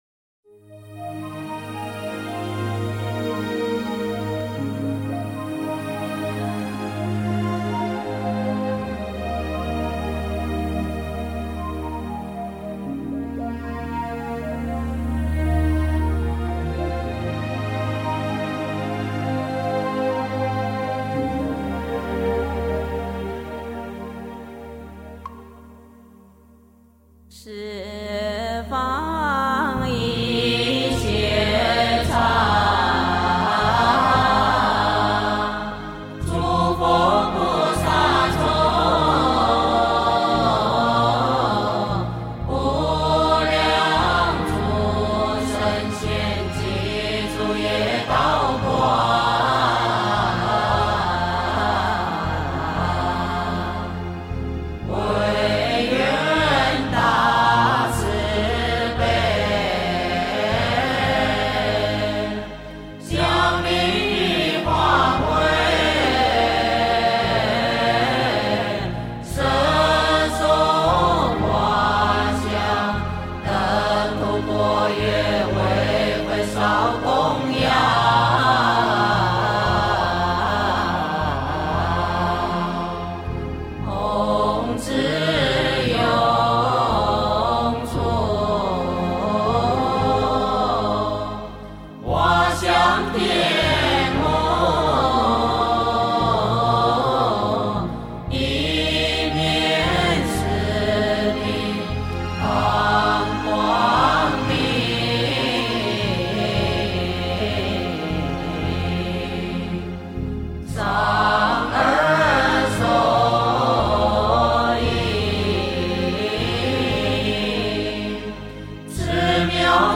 十方一切刹--佛光山梵呗 经忏 十方一切刹--佛光山梵呗 点我： 标签: 佛音 经忏 佛教音乐 返回列表 上一篇： 普贤十大愿--佛光山梵呗 下一篇： 佛说阿弥陀经--如是我闻 相关文章 杨枝净水赞--天籁之音 杨枝净水赞--天籁之音...